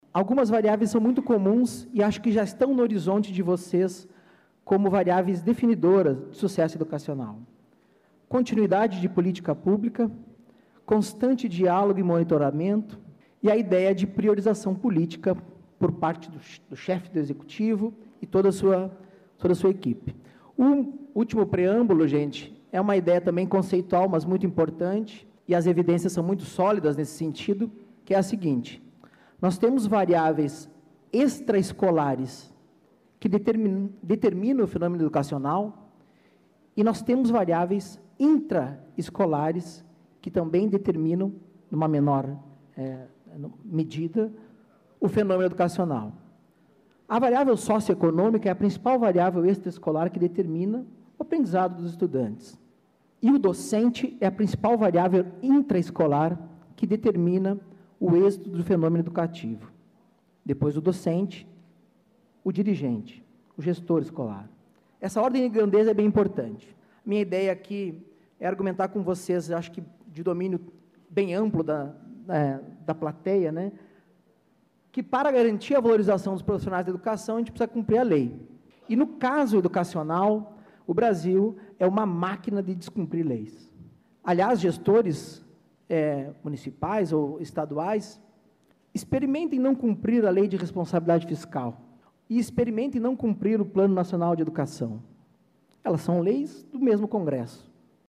IV Sined e III Encontro de Promotores e Promotoras de Justiça da Educação - áudios dos participantes
Painel "Perspectivas para a valorização dos profissionais de educação"